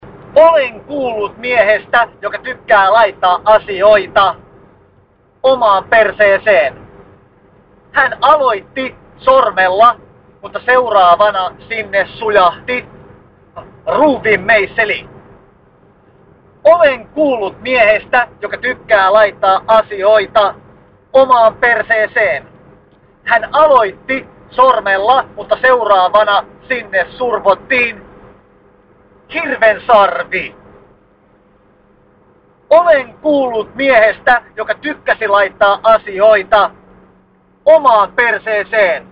Hän on a cappella -lauluyhtye, jonka jäsenet ovat suurelta osin lahtelaistuneita.
Sisäpiiri on jo leimannut kaverin kaverin Hänen ehkä parhaimmaksi kappaleeksi! arvoa nostaa kenties se, että Kaverin kaveri on äänitetty autoa ajaessa!